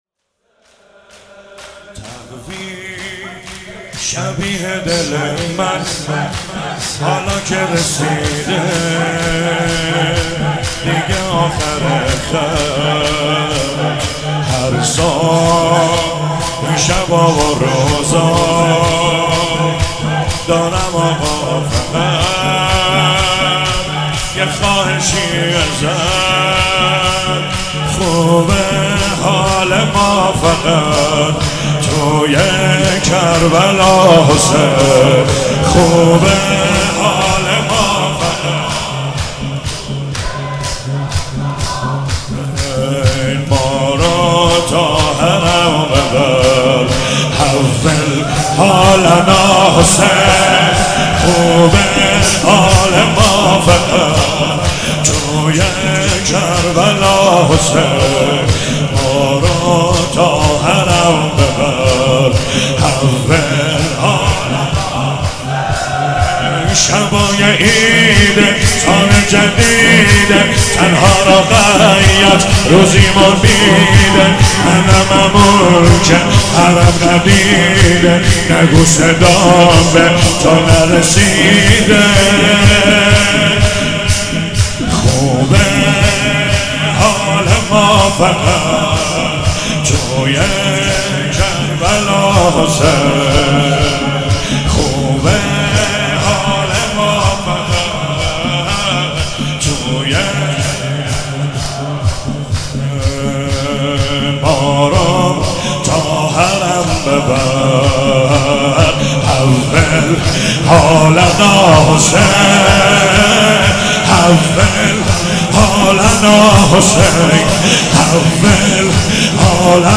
هفتگی 24 اسفند - شور - تقویم شبیه دل من حالا که رسیده